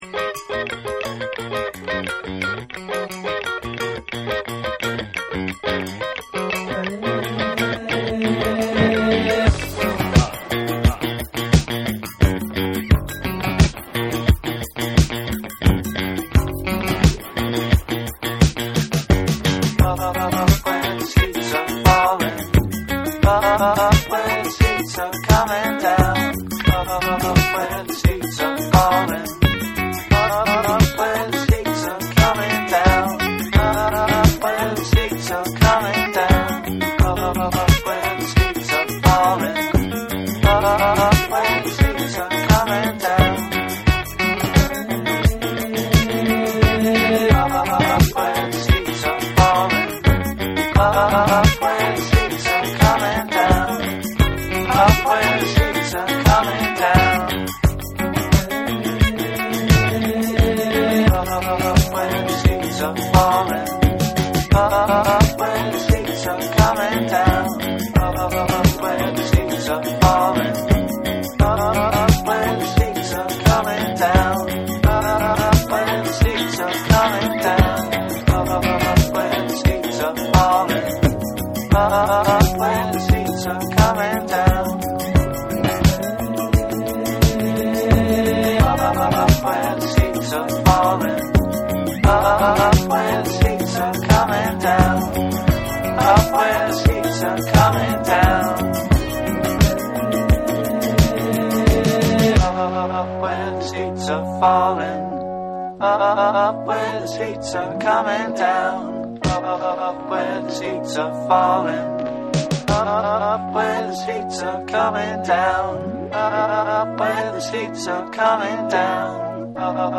気だるさの中にも心地よさを漂わすメロディック・サイケ・ディスコ